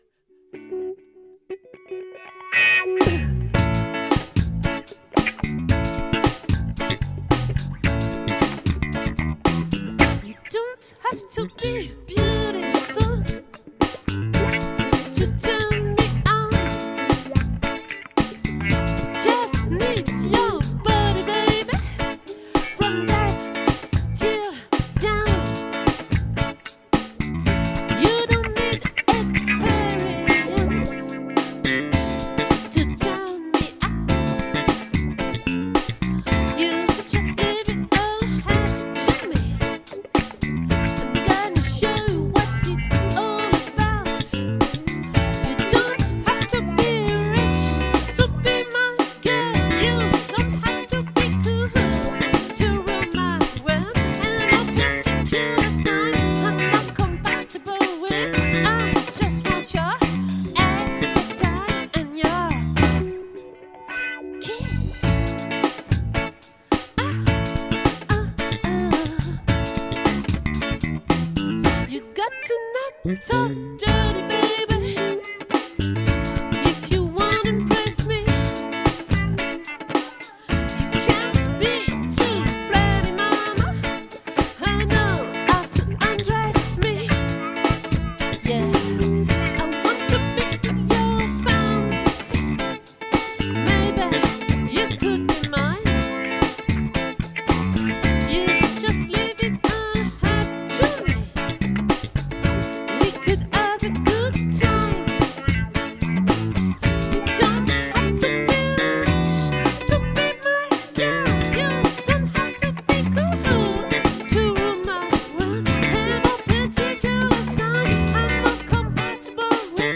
🏠 Accueil Repetitions Records_2024_03_13